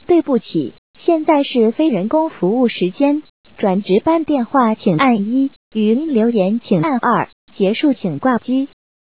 假期提示音.wav